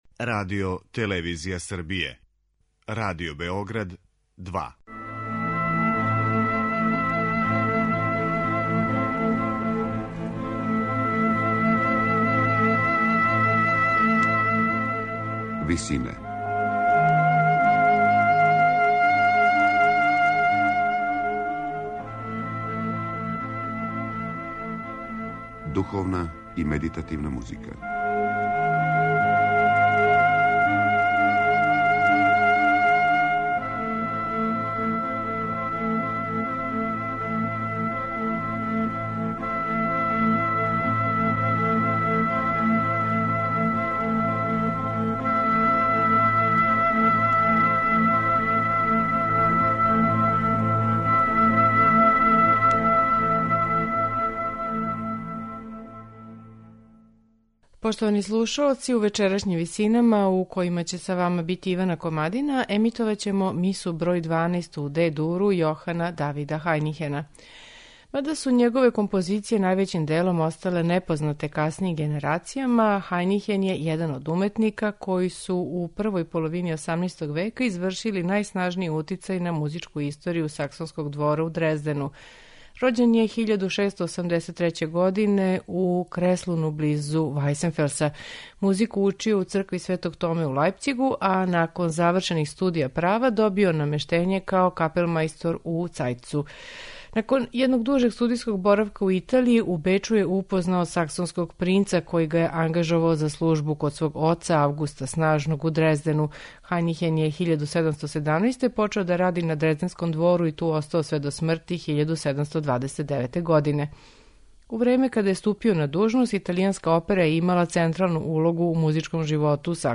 Хајнихенова Миса број 12 у Дe-дуру, коју ћемо слушати у вечерашњим Висинама, типичан је пример развијене барокне мисе. Сваки од ставова ординаријума подељен је у мање одсеке различитог трајања, музичке структуре и оркестрације.
сопран
алт
тенор
басови
камерног хора